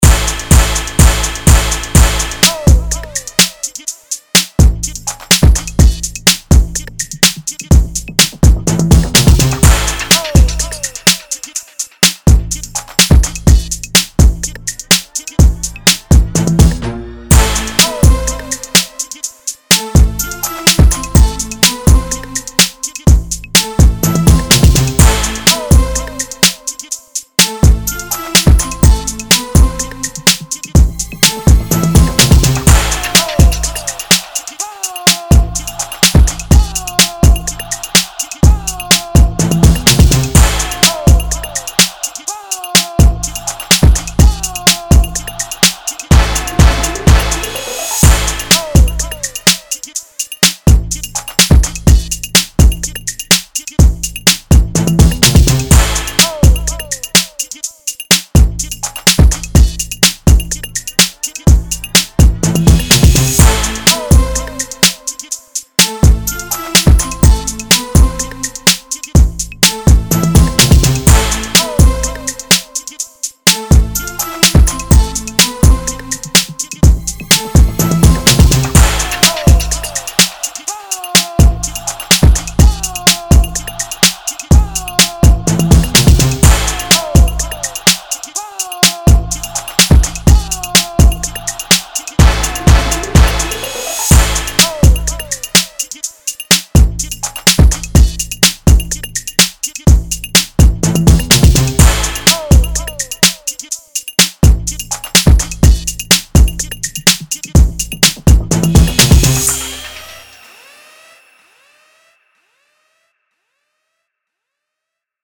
Hip Hop, Rap, 80s, Dance
Bm